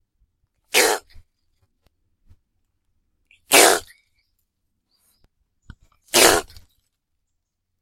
先日ハクビシン♂を捕獲した際に、持ち合わせていたリニアPCMレコーダーでハクビシンの威嚇音を録音することができたので公開する。
音声は、2分間弱の間に3回鳴いたものを24bit/96kHzで録音し、空白を詰めた上でmp3に書き出している。
Paguma-larvata.mp3